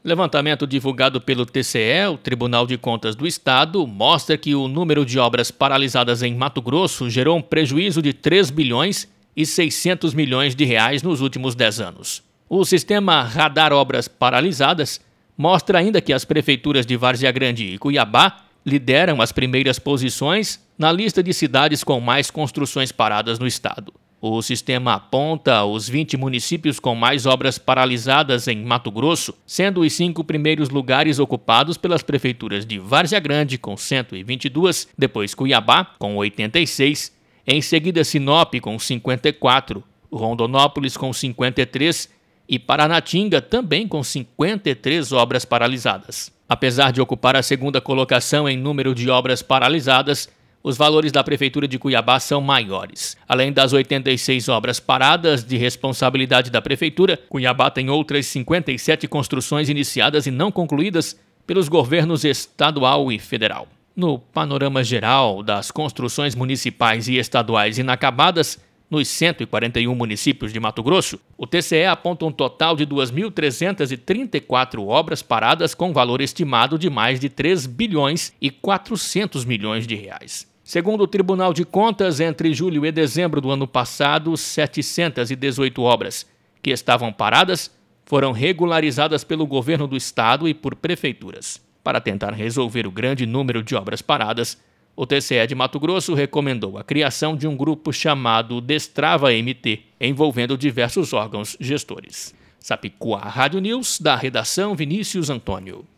Boletins de MT 17 fev, 2022